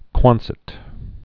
(kwŏnsĭt)